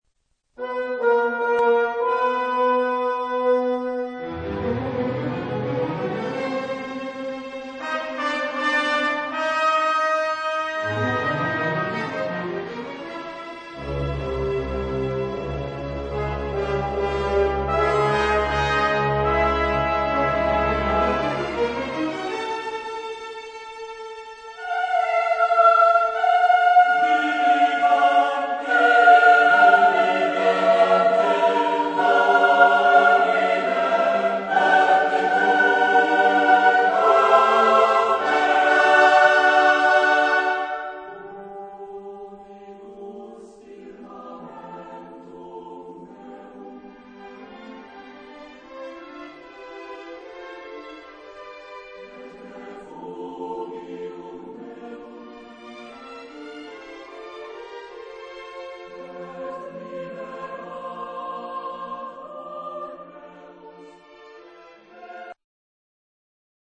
Genre-Style-Form: Psalm ; Sacred ; Contemporary
Type of Choir: SATB (div)  (4 mixed voices )
Instrumentation: Orchestra  (19 instrumental part(s))
Tonality: atonal